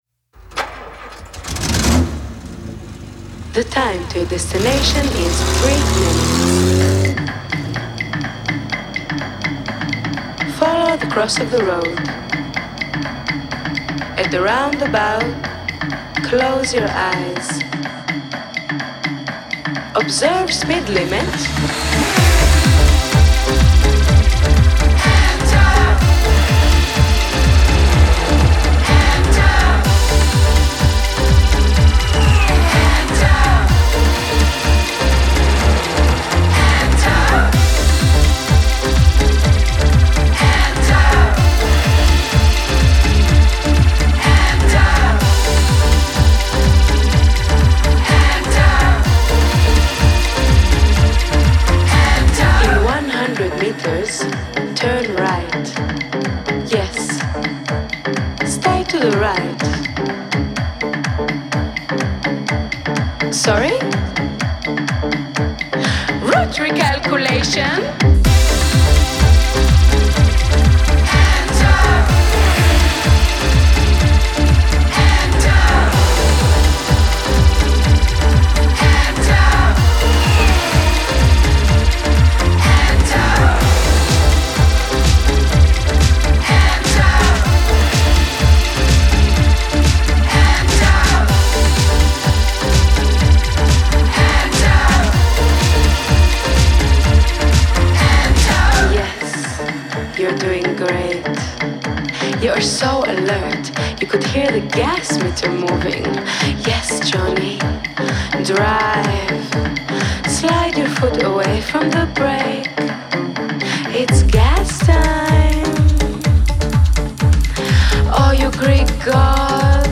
Genre: Pop, Pop Rock, Synth